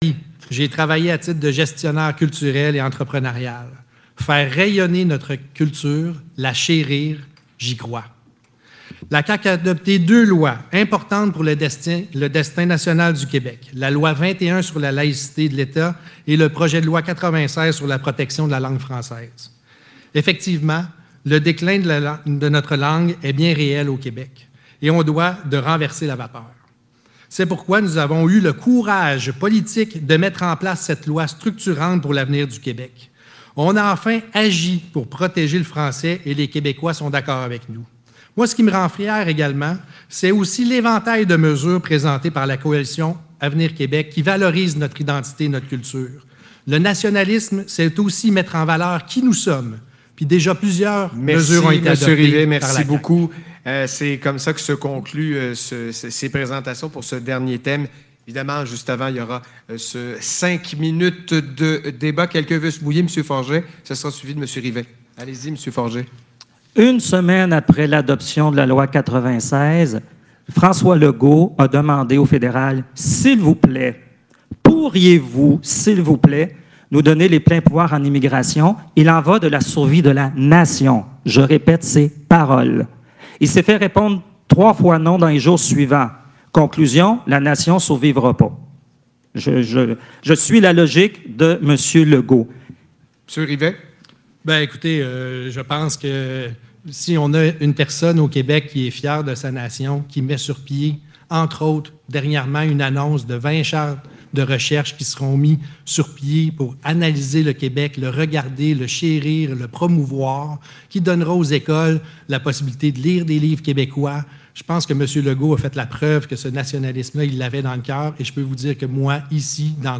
Débat politique présenté le 21 septembre dernier à la Salle André Gagnon de La Pocatière en vue des élections générales du 3 octobre 2022.
debat-nationalisme-debat-5-minutes-.mp3